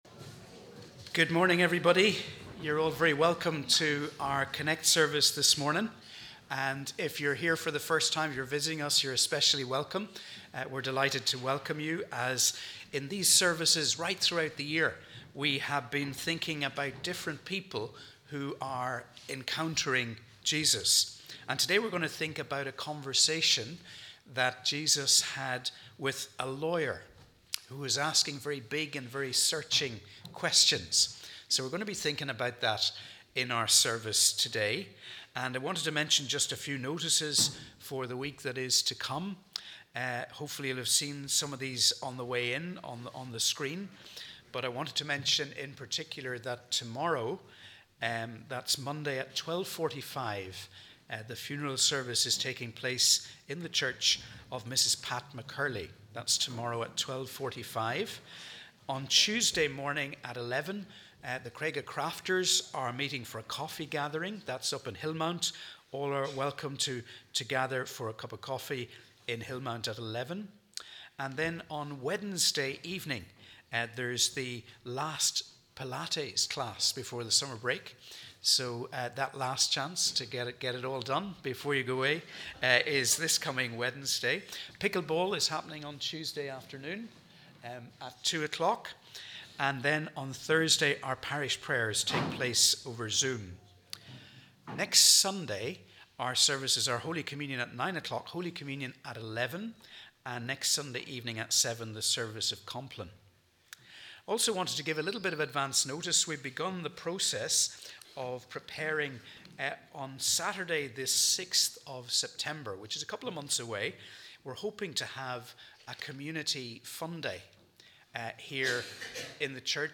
We warmly welcome you to our CONNEC+ service as we worship together on the 3rd Sunday after Trinity.